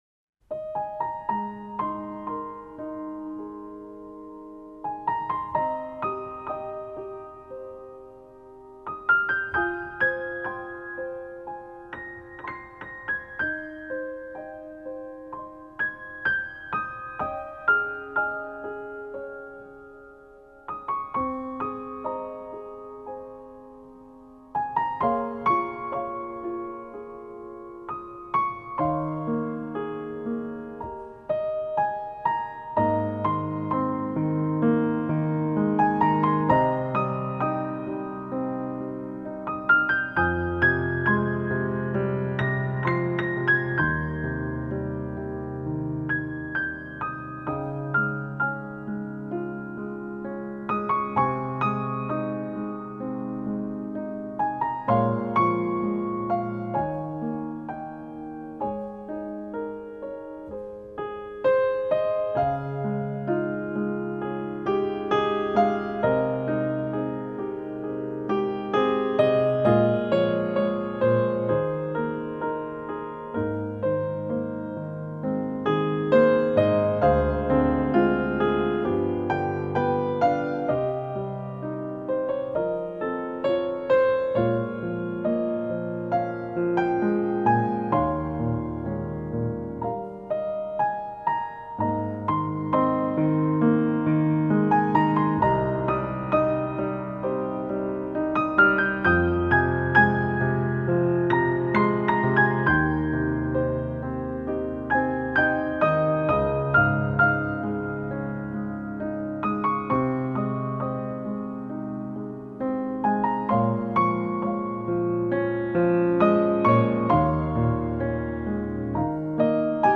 یکی از زیباترین و آرامش بخش ترین آهنگ های بیکلام